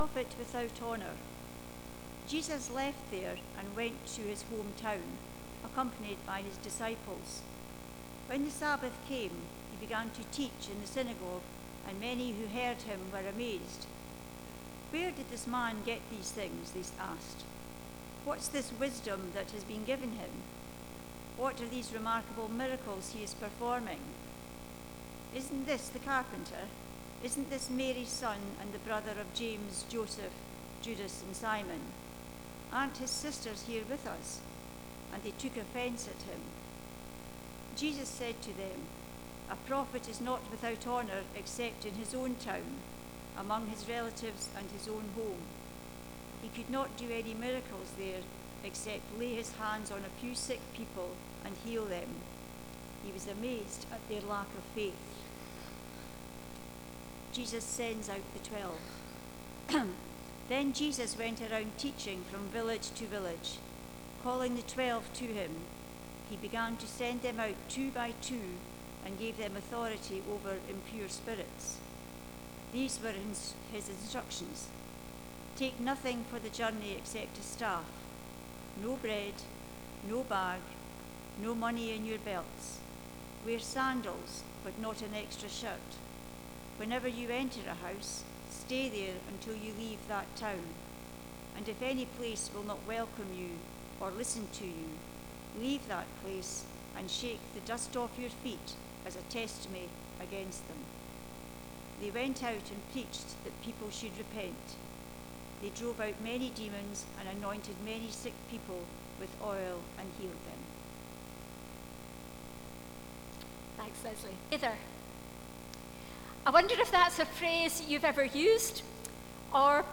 Passage: Mark 6: 1-13 Service Type: Sunday Morning